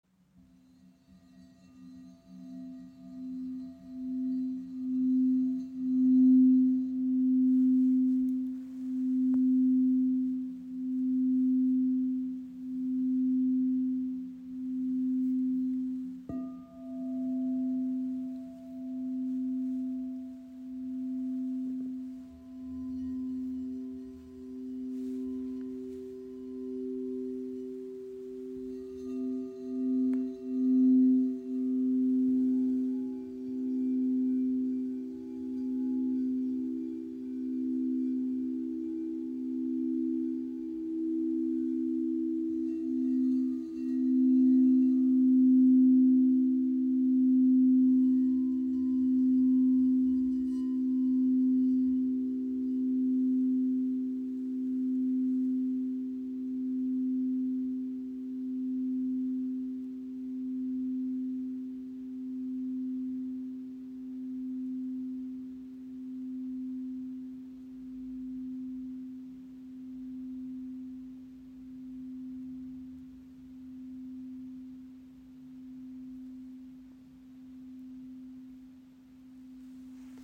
Klangbeispiel
Gefertigt aus reinem Quarzglas erzeugen sie klare, lang schwingende Töne, die Körper und Seele tief berühren.
Weiterlesen Klangbeispiel Kristallklangschalen C4 - G4 und E4 in 432 Hz (Handyaufnahme)